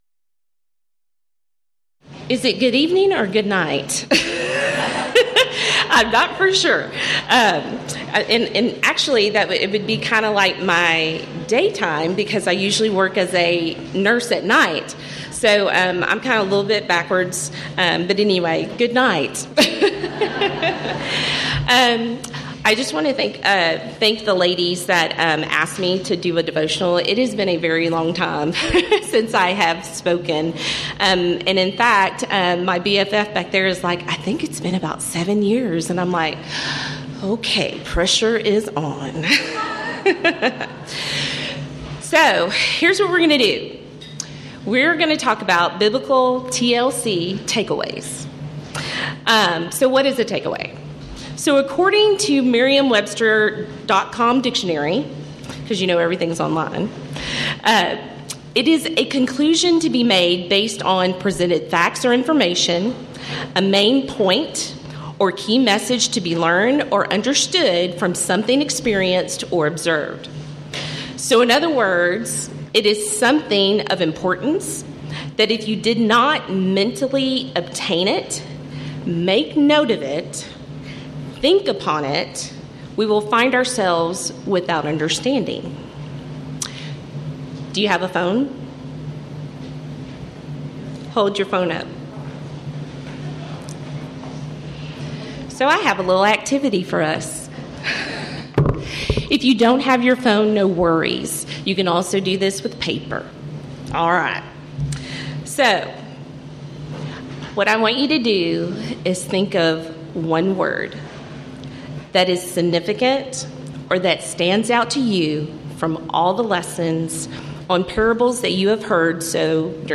Title: Friday Evening Devotional
Event: 9th Annual Texas Ladies in Christ Retreat Theme/Title: Studies in Parables